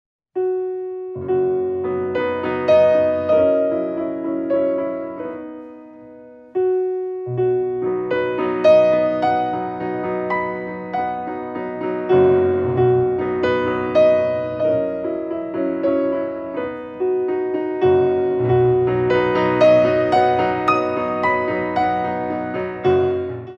4x6 9/8